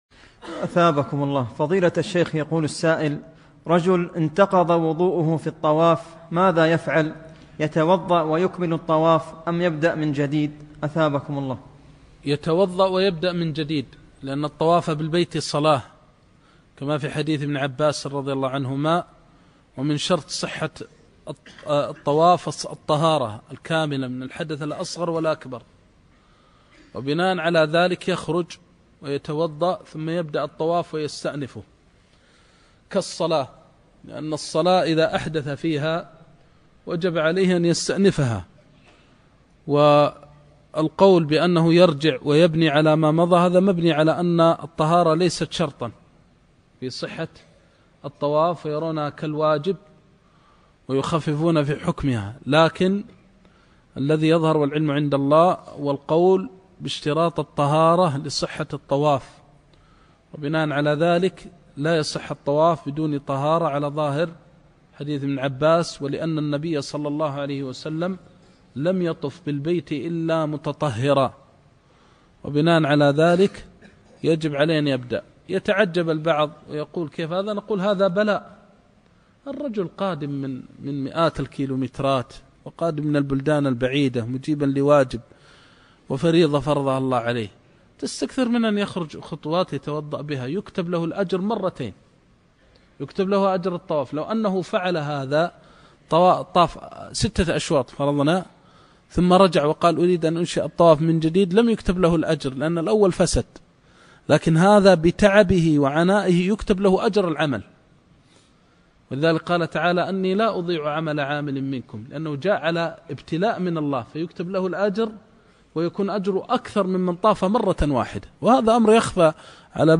رجل أحدث اثناء الطواف ماذا يفعل ؟ (موعظة نفيسة)